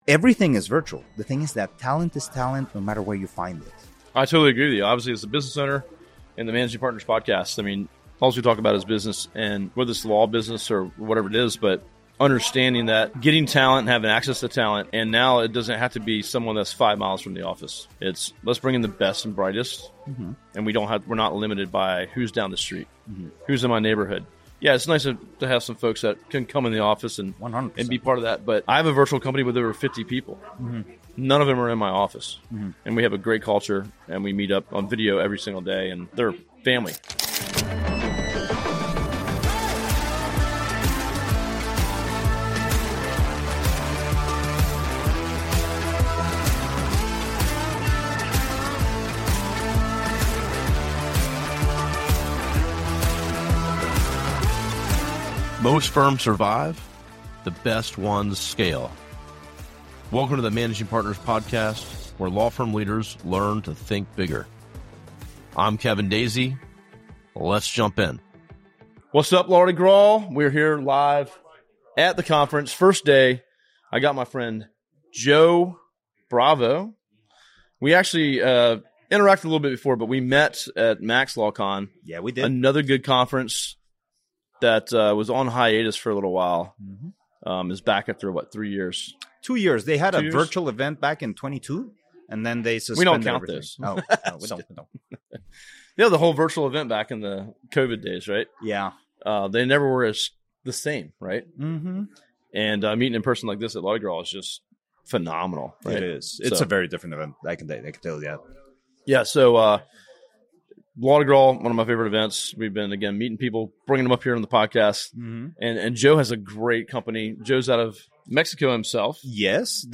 In this energetic live session from Law-Di-Gras